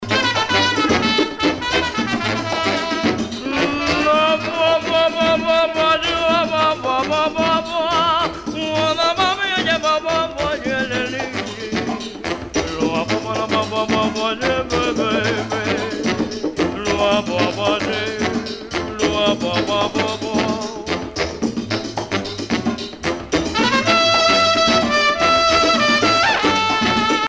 danse : mambo